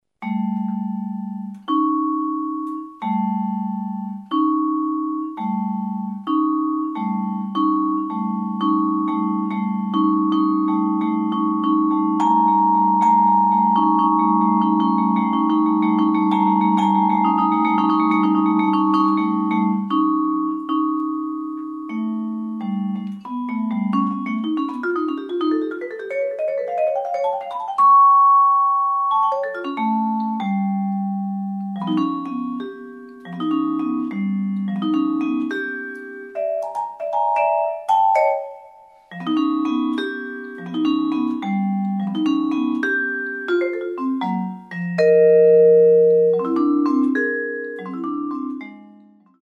Genre: Vibraphone (4-mallet)
Three jazz vibraphone pieces of distinct character.
Vibraphone (3-octave)